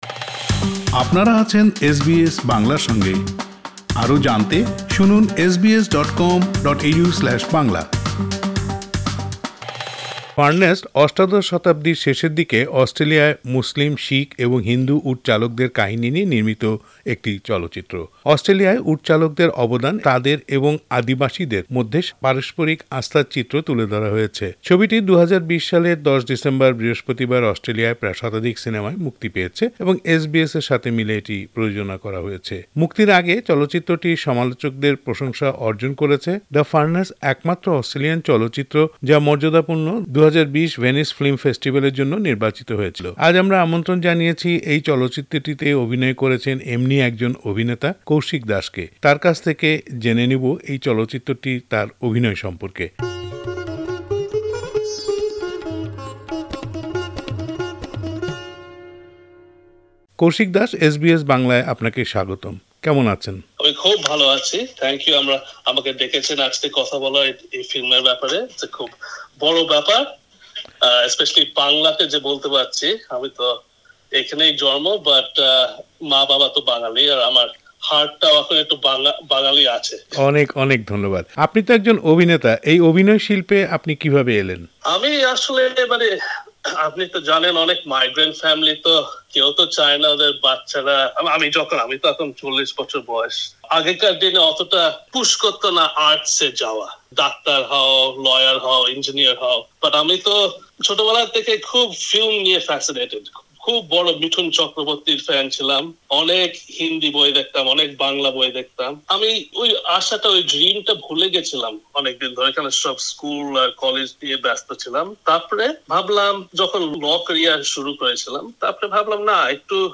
এসবিএস বাংলার সাথে কথা বলেছেন